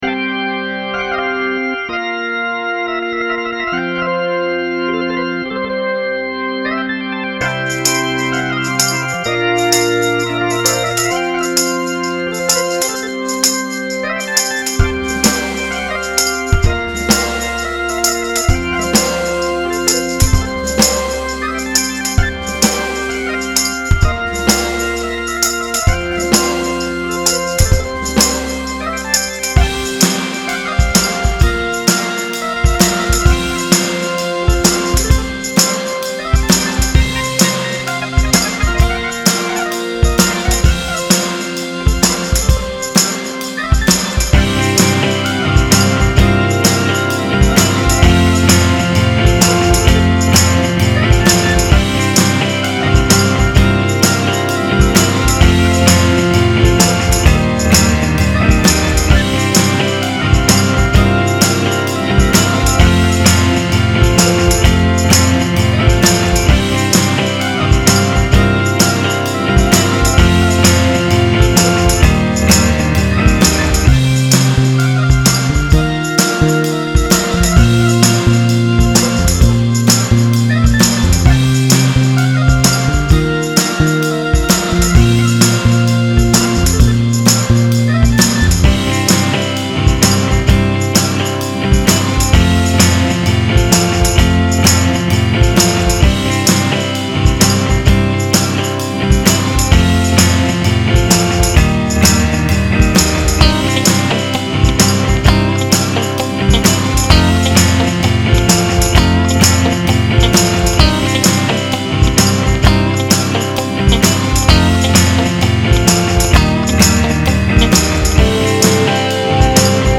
Aquí les dejo una canción que formé de esa manera: juntando loops a ver cómo salía:
Bluesy , es una canción “southern rock” con una dósis de gospel.
bluesy.mp3